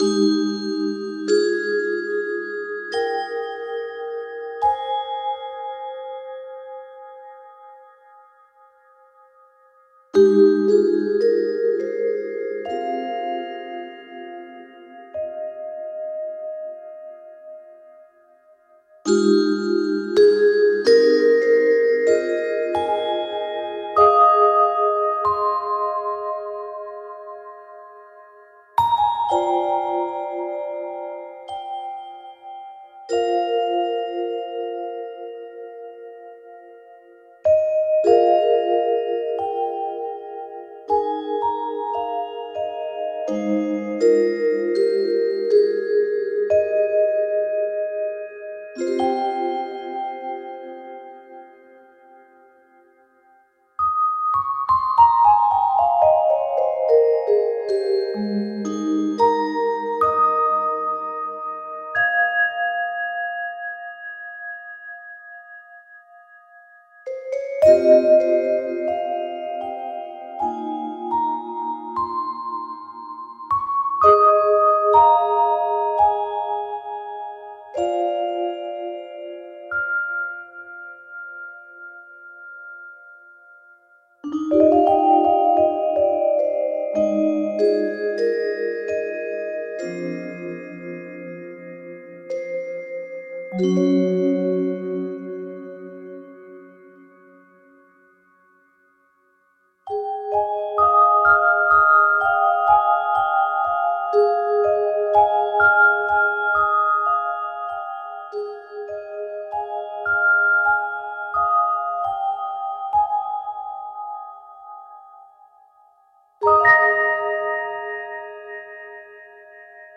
Austere, personal, dreamy music.
Tagged as: Ambient, Other